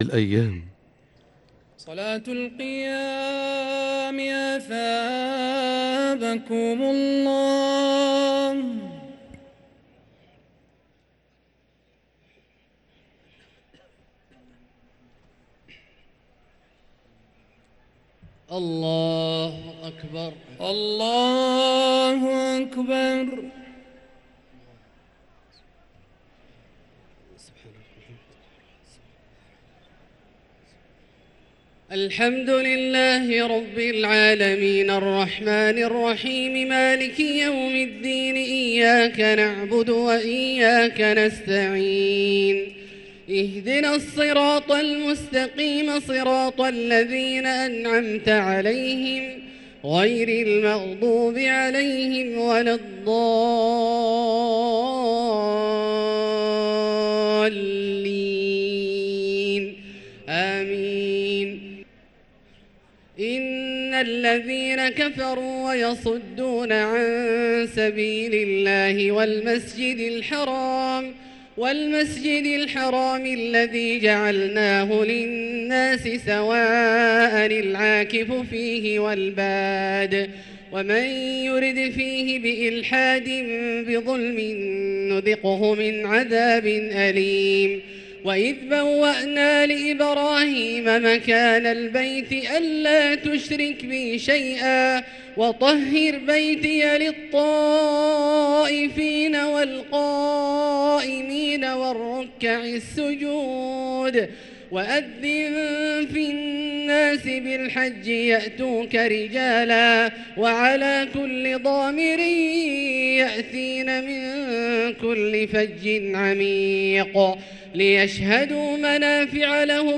صلاة التراويح ليلة 22 رمضان 1444 للقارئ عبدالله الجهني - الثلاث التسليمات الأولى صلاة التراويح